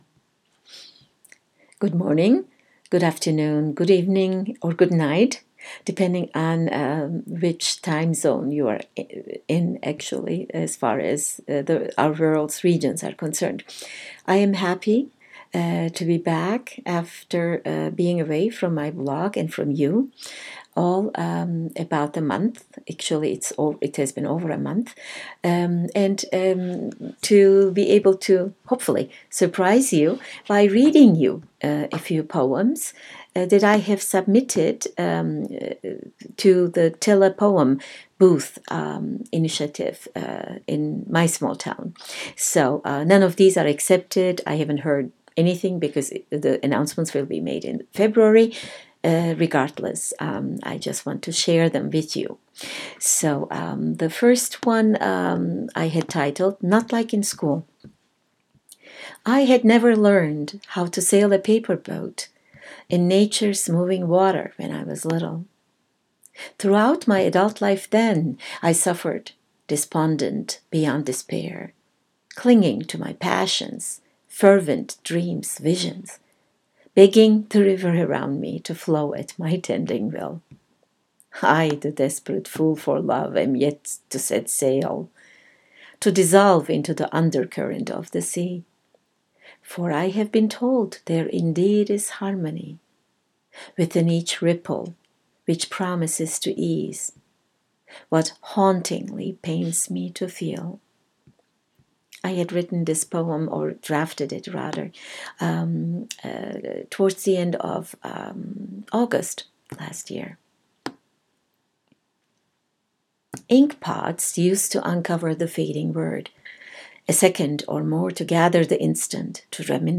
poems-read-as-the-1-15-17-post.m4a